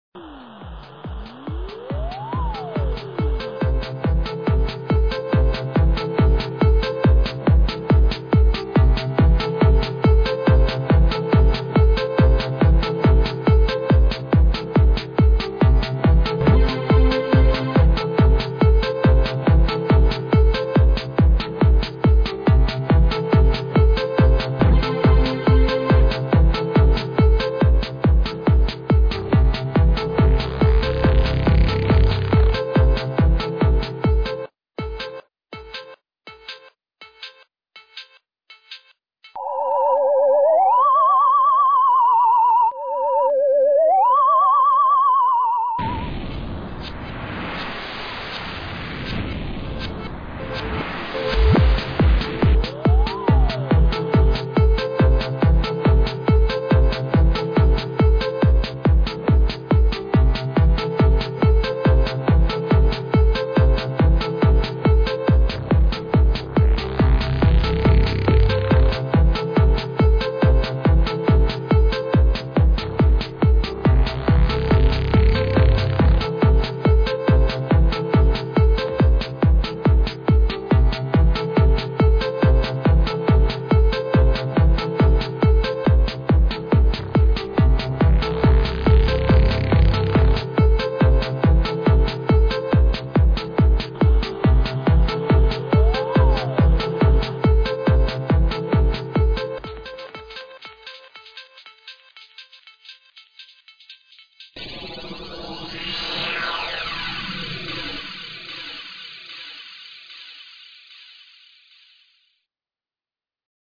Electronic
instrumental with more sound effects